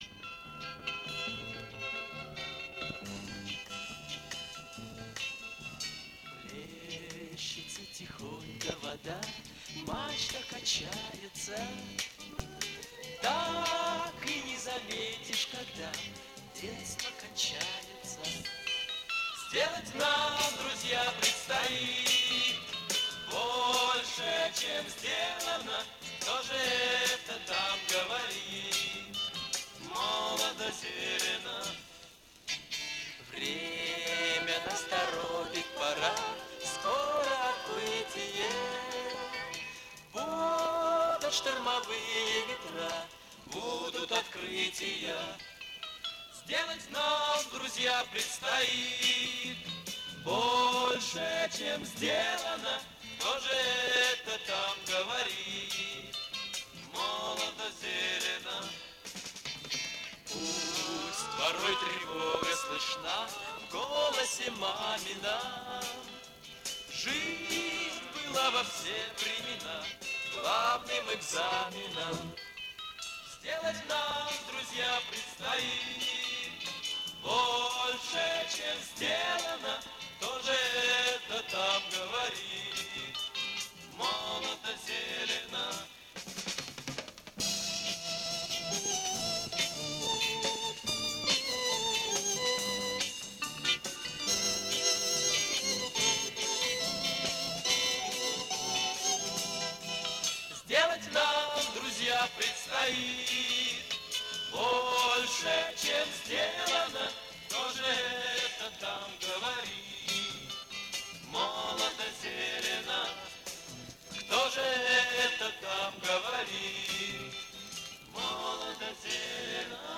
ВИА
Моно.